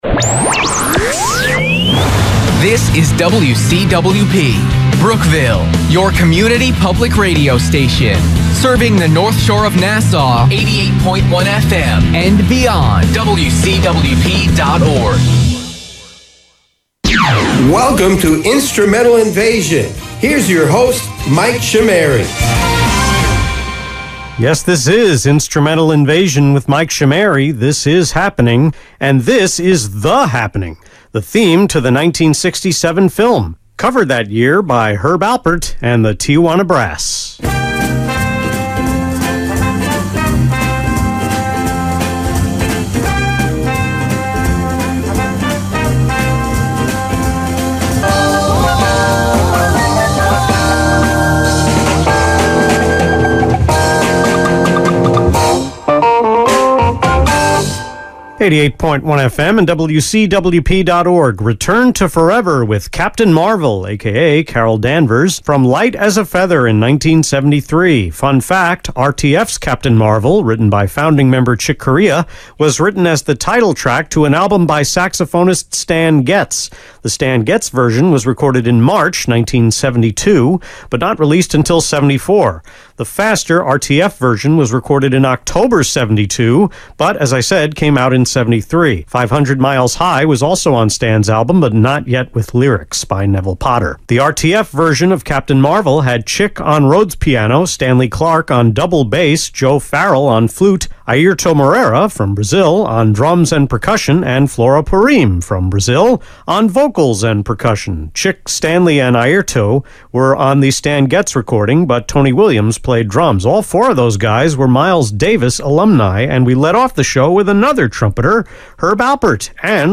The special 2/2/22 edition of Instrumental Invasion on WCWP was recorded from December 13 to 15, 2021: one segment on the 13th, two on the 14th, and the second hour on the 15th.
Every segment but the last had to be padded out with extra liners, script additions, and ad-libs.
Airchecks